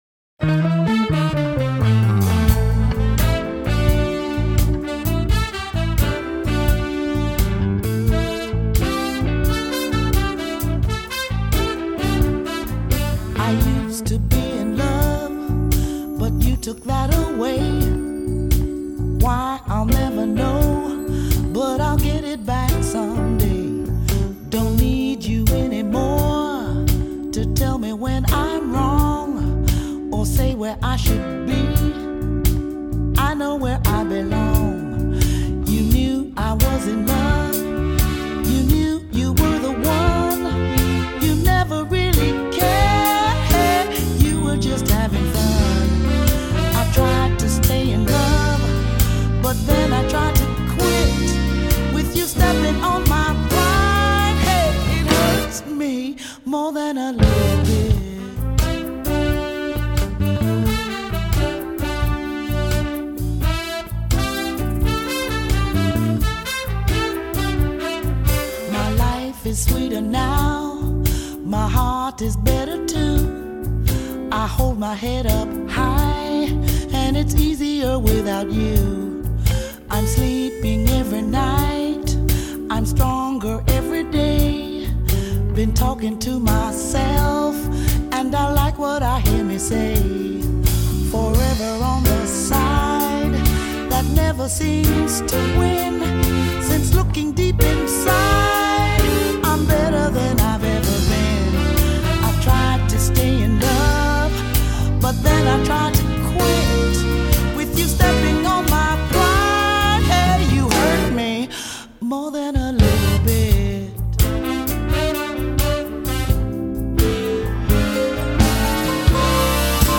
Funk / Soul
Contemporary Jazz
Fusion
• Fortepian
• Kontrabas
• Perkusja
• Gitara
• Saksofon, flet
• Puzon
• Trąbki
• Wokale wspierające
• Mastering analogowy, dedykowany do winylu